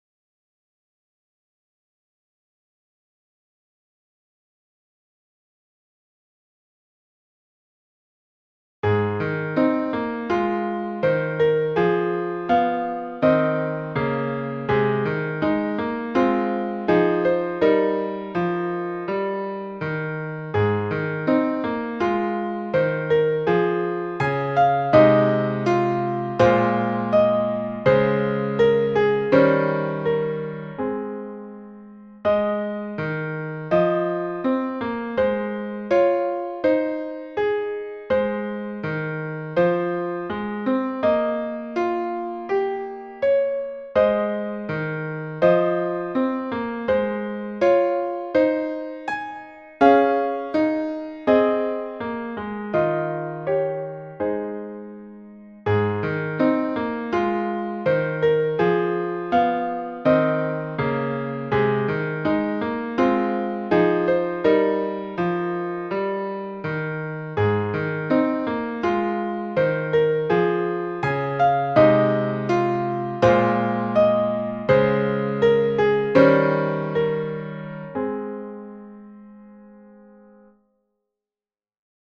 Pas à pas – piano à 82 bpm
Pas-a-pas-piano-a-82-bpm.mp3